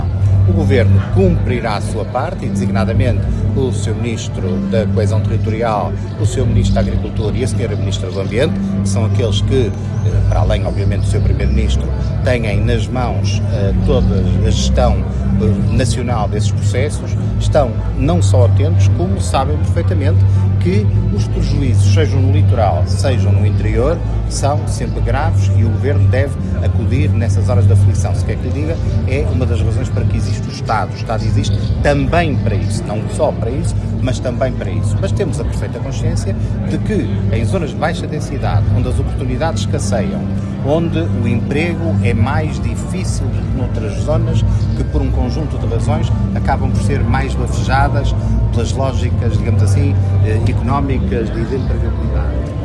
O Ministro dos Assuntos Parlamentares, Carlos Abreu Amorim, visitou, a 18 de abril, a Feira Medieval de Torre de Moncorvo, em representação do Governo, reforçando a atenção do Executivo às problemáticas do interior, numa altura em que a região enfrenta prejuízos significativos provocados pelo mau tempo.
Relativamente aos prejuízos registados no concelho, nomeadamente no Vale da Vilariça, o ministro garantiu que o Governo está a acompanhar a situação e que haverá resposta por parte do Estado: